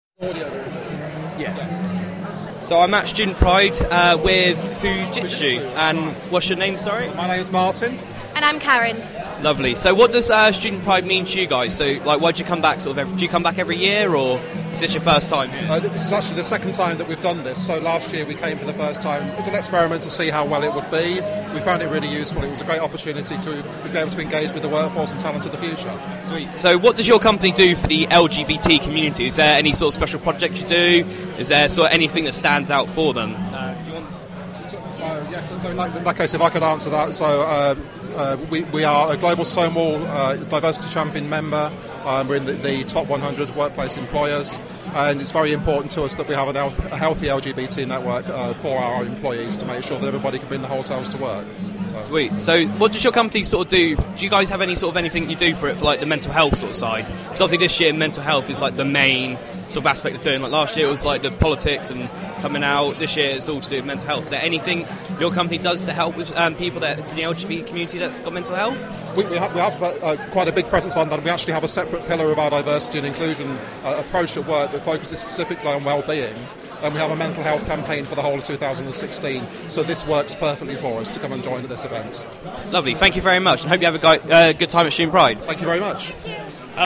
Interview with the lovely people at Fujitsu